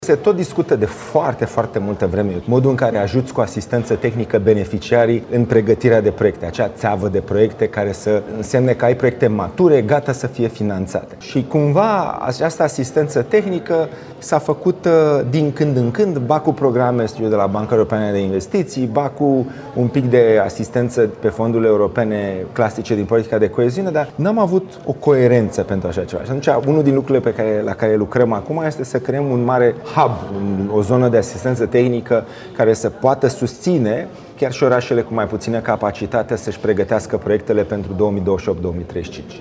Prezent la Timișoara Cities Summit, ministrul Investițiilor și Proiectelor Europene, Dragoș Pîslaru, a anunțat crearea unei zone de asistență care va ajuta comunitățile locale să scrie proiecte europene pentru perioada 2028-2035.